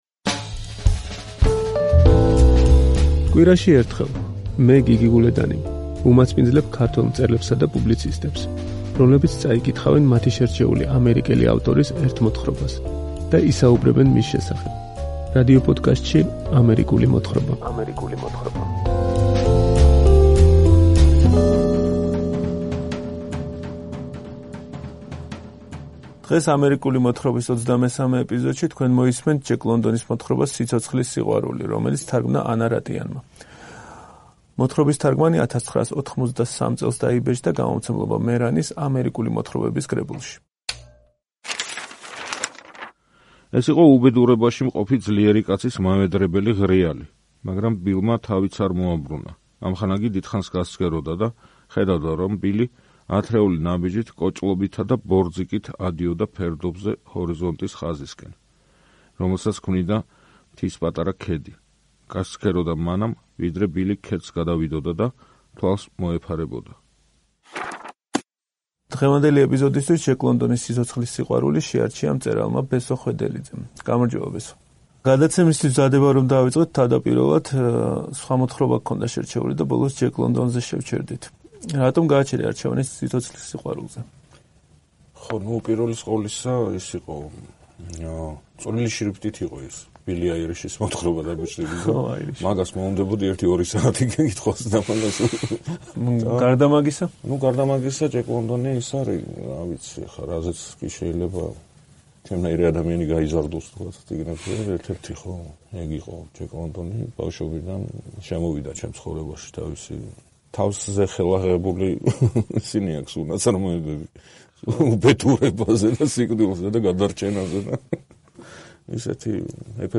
კითხულობს ჯეკ ლონდონის მოთხრობას “სიცოცხლის სიყვარული”.